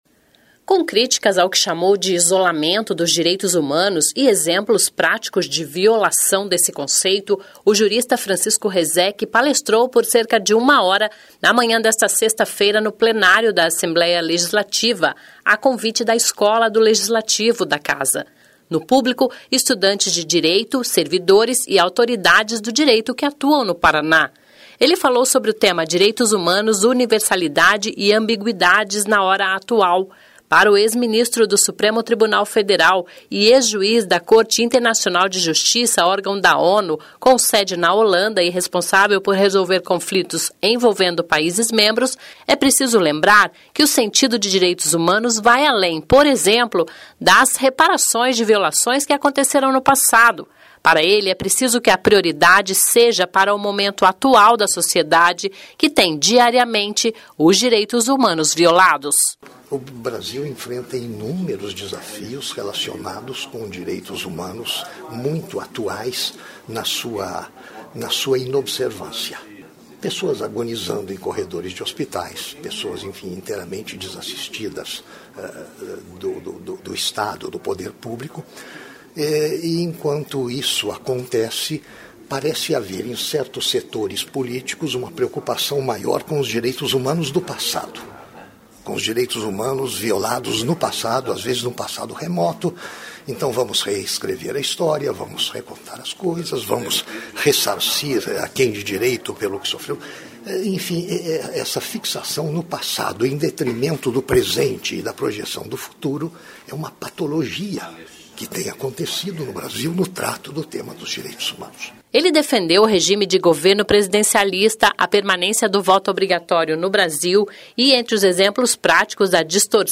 (Sonora)
repórter